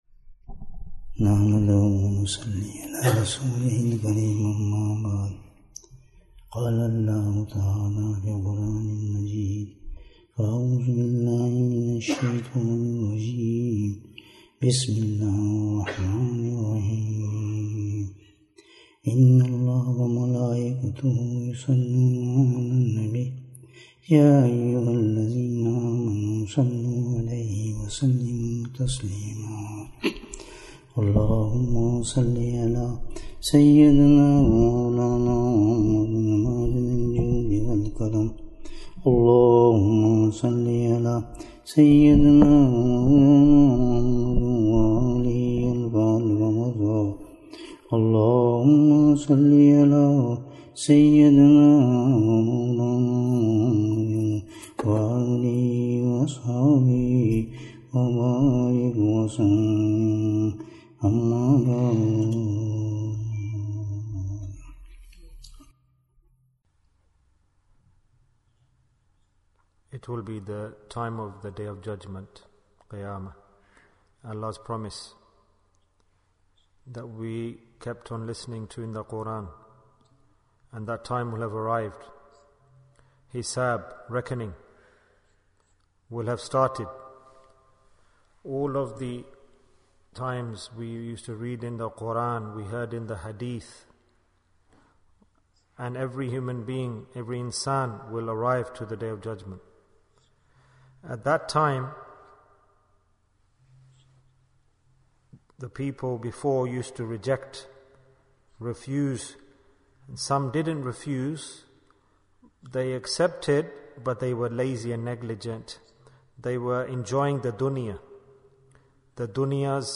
What Will be the Deed for Forgiveness? Bayan, 72 minutes11th February, 2023